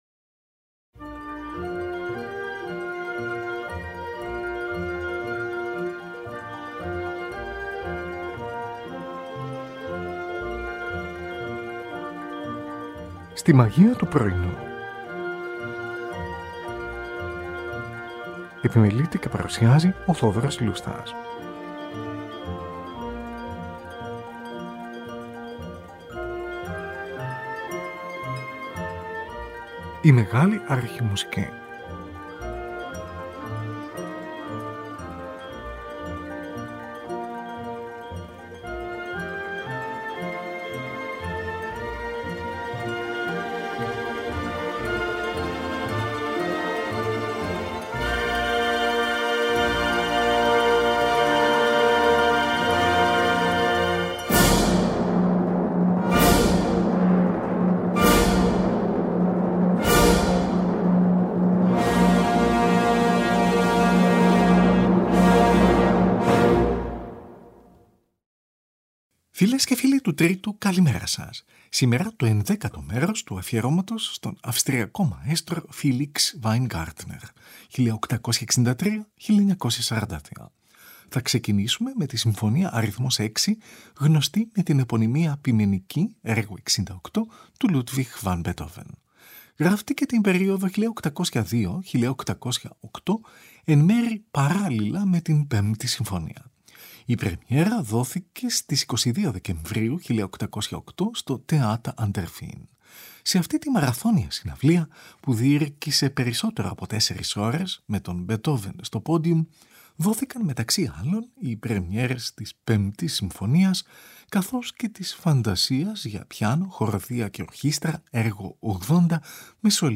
συμφωνία αρ.6